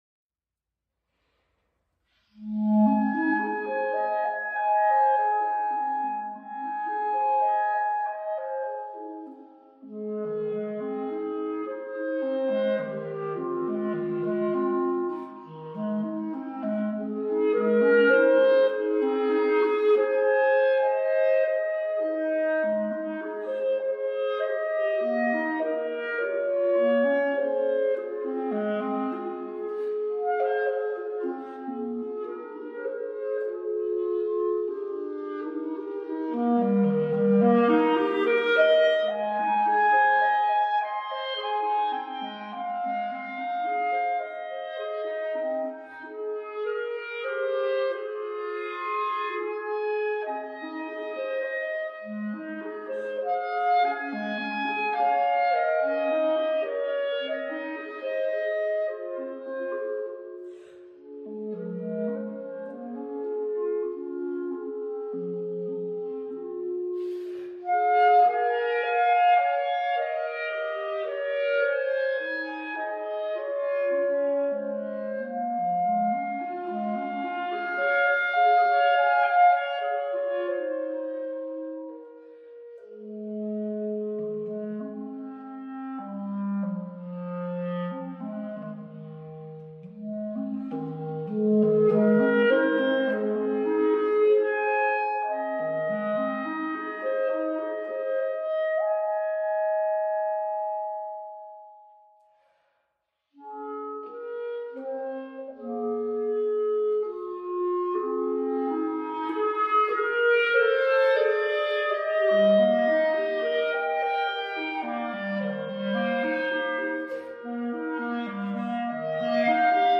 编制：Cl / Cl
B♭ Clarinet 1
B♭ Clarinet 2
演奏时建议像原曲演唱那样不受节拍束缚自由演绎。